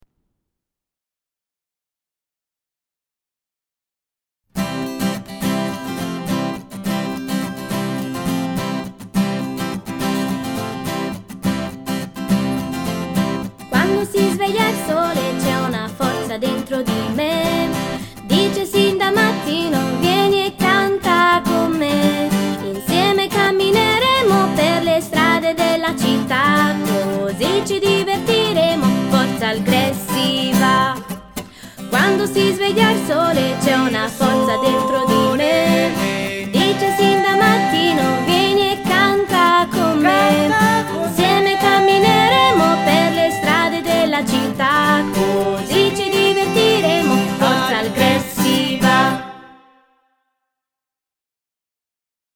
Filastrocca